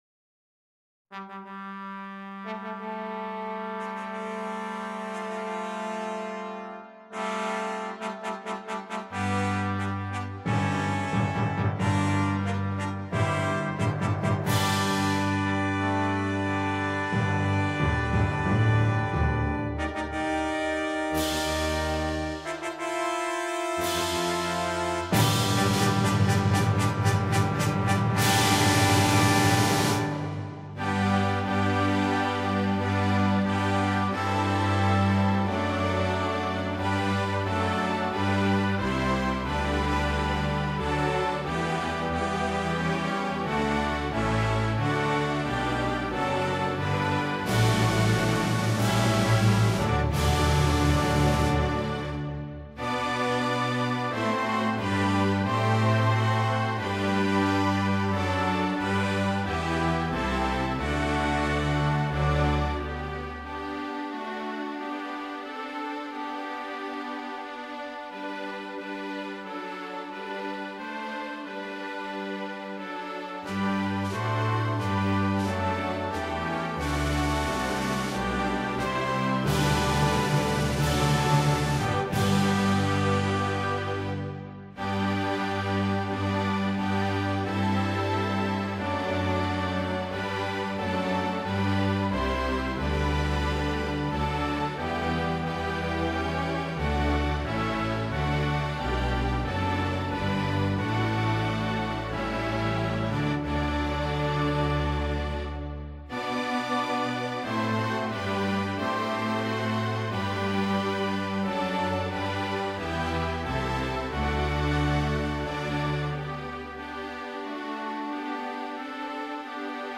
Orchestra
An arrangement for orchestra
with effective but playable fanfare.